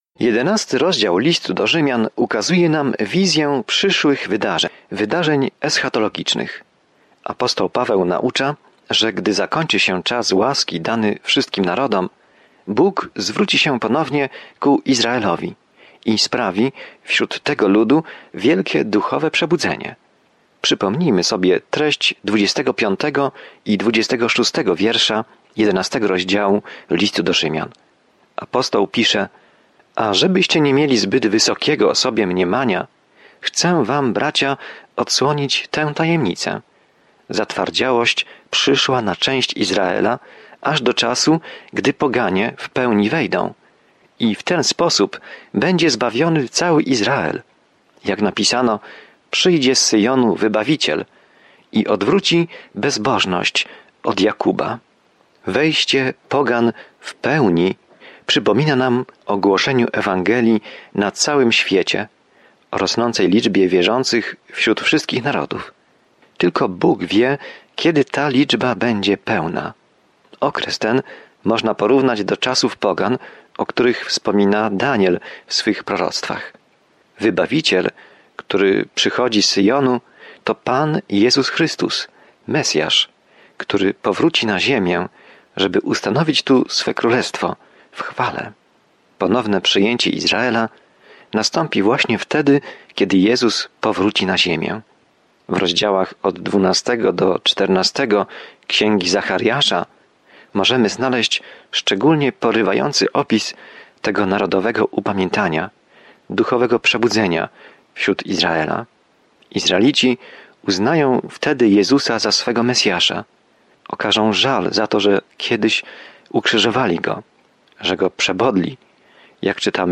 Pismo Święte Rzymian 11:30-36 Rzymian 12:1-2 Dzień 26 Rozpocznij ten plan Dzień 28 O tym planie List do Rzymian odpowiada na pytanie: „Jaka jest dobra nowina?” I jak każdy może uwierzyć, zostać zbawiony, uwolniony od śmierci i wzrastać w wierze. Codzienna podróż przez List do Rzymian, słuchanie studium audio i czytanie wybranych wersetów słowa Bożego.